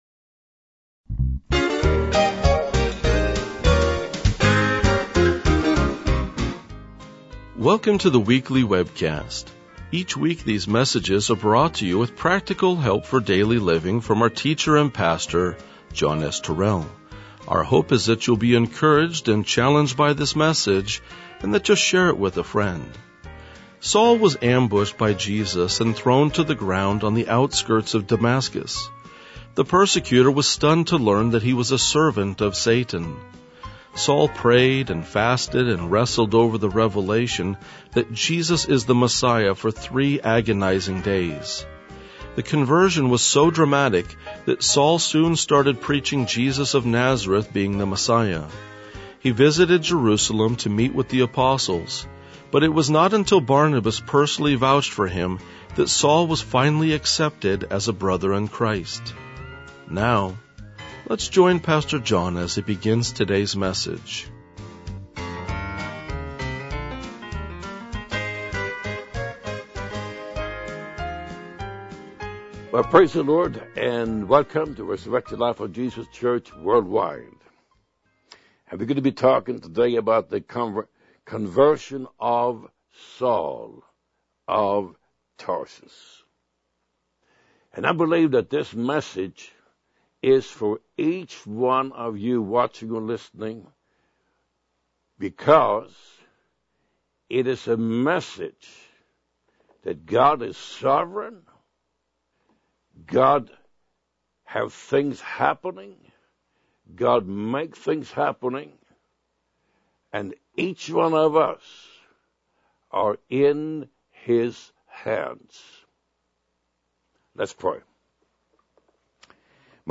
RLJ-2006-Sermon.mp3